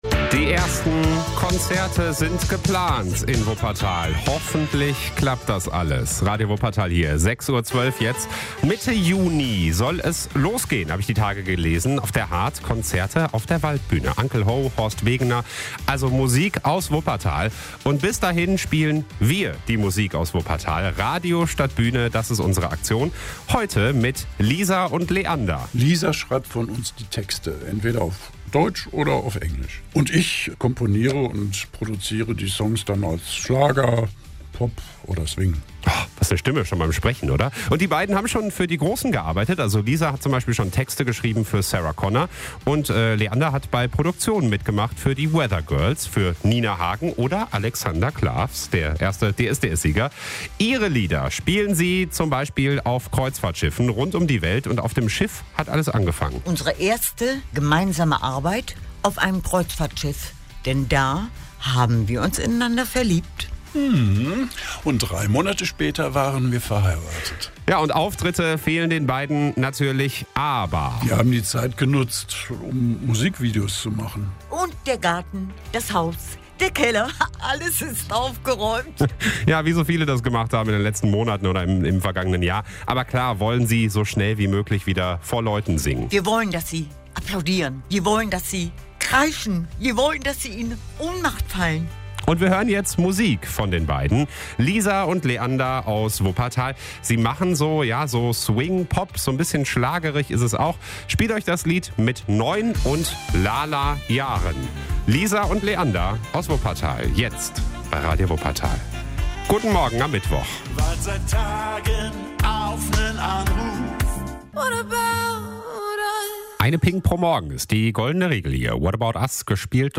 Schlager/Pop (deutsch und englisch.